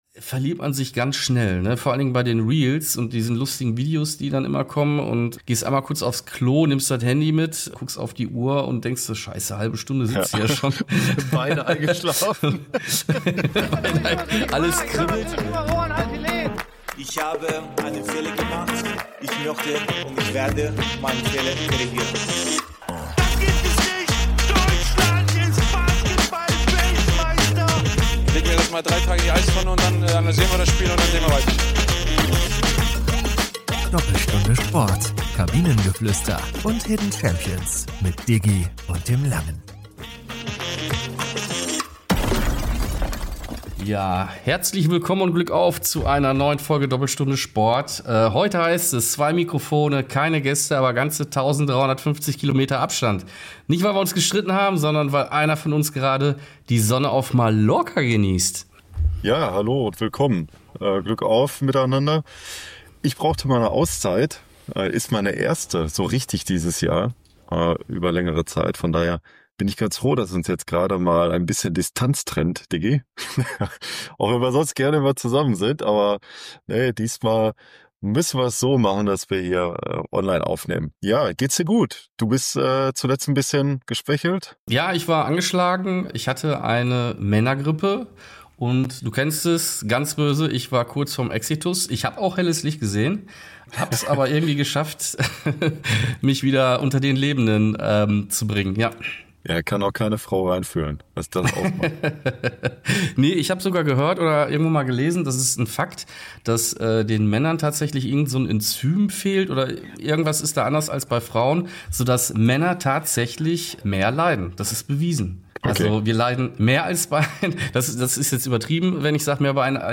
Kurz gesagt: Wir sind zurück – leicht übermüdet, gut gelaunt und mit mehr Gesprächsbedarf als je zuvor!